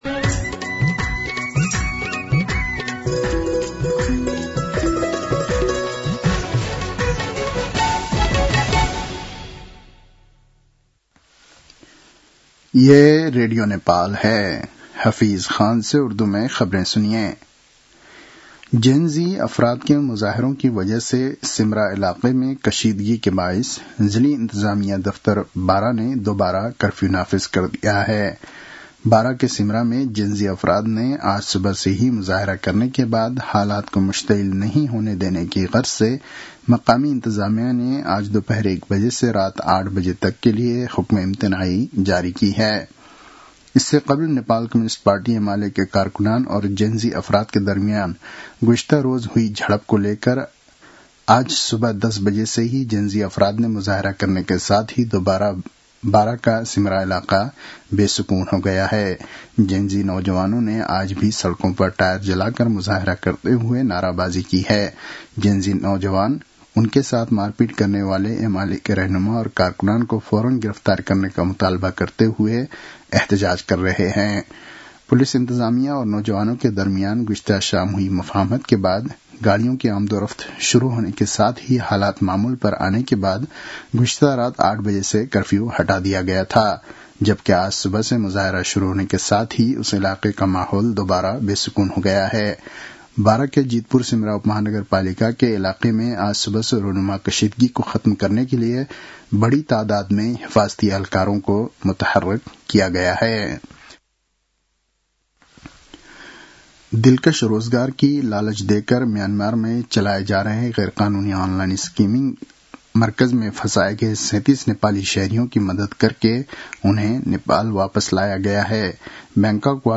उर्दु भाषामा समाचार : ४ मंसिर , २०८२